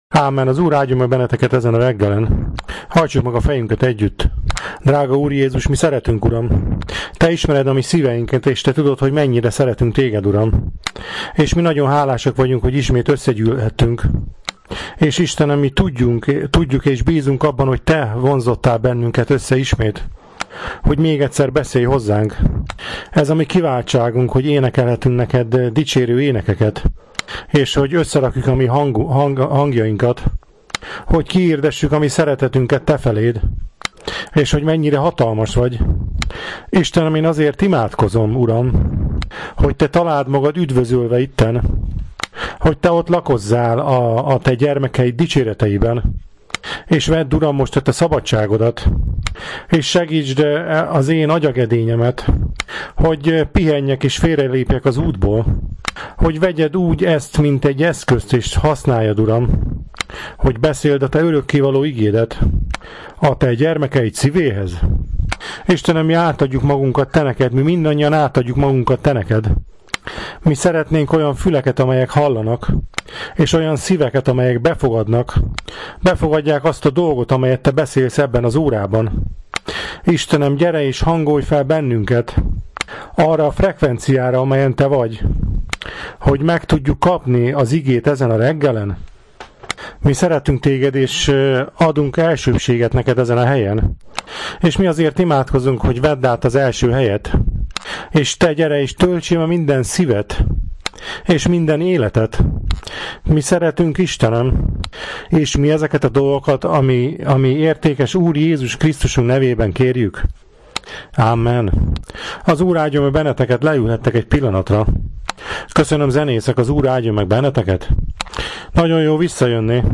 Prédikációk